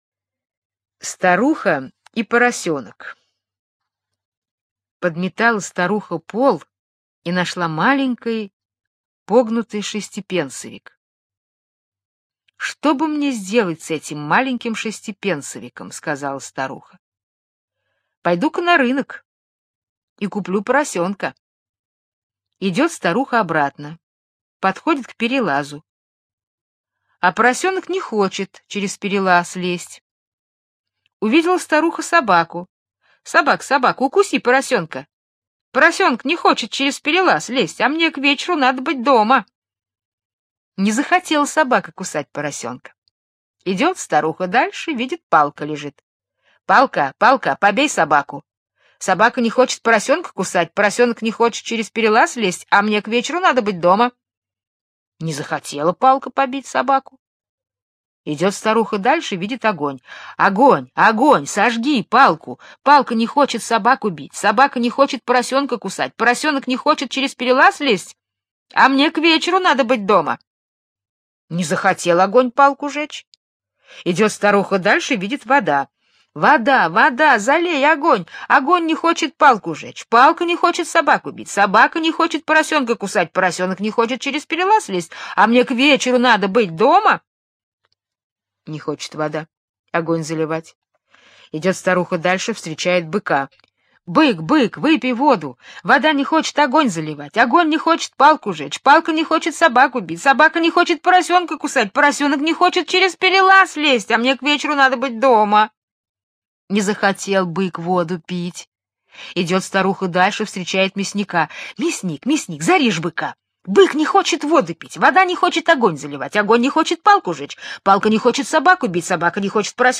Старуха и поросенок - британская аудиосказка - слушать онлайн